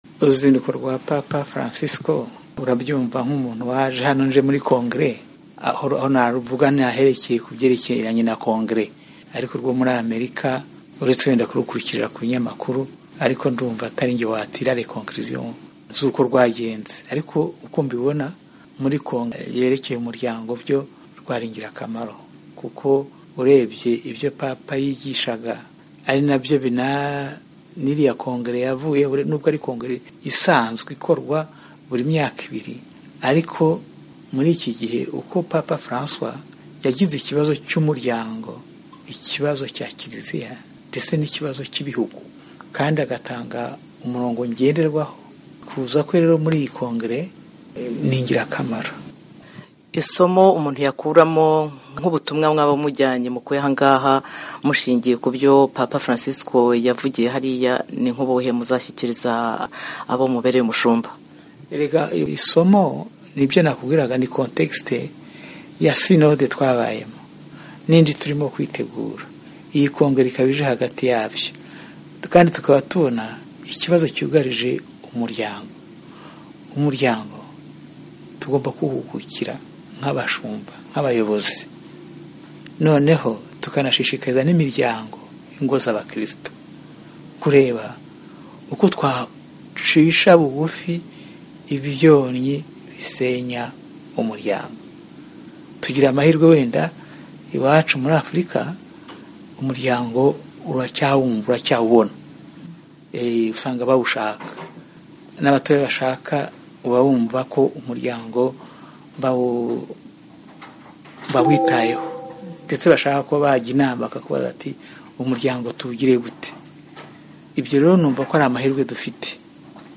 Mu bibazo yamubajije, harimo uko yabonye uru ruzinduko.